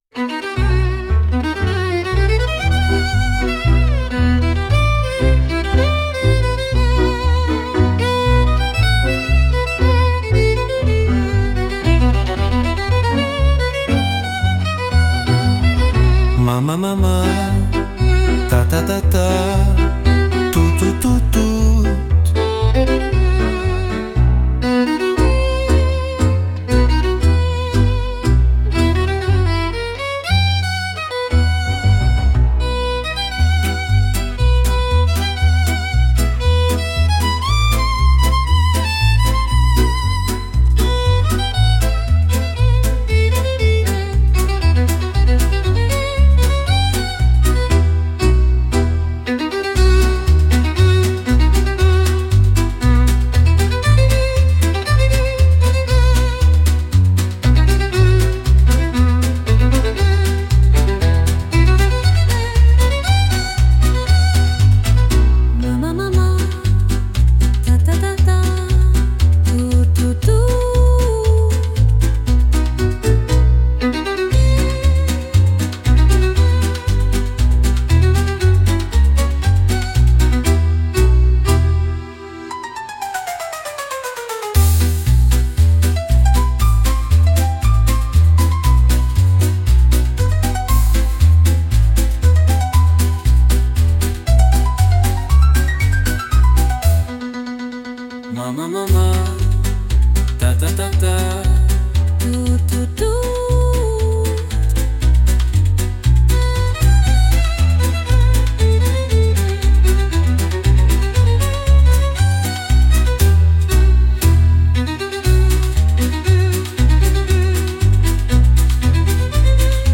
With Vocals / 歌あり
A passionate tango track led by a dramatic violin melody.
艶やかなバイオリンの旋律が響く、情熱的なタンゴ・ナンバー。
男女のスキャット（声）が掛け合うように入り、まるで二人がダンスを楽しんでいるような情景を描き出します。
大人の雰囲気を漂わせつつも、どこか遊び心のあるサウンド。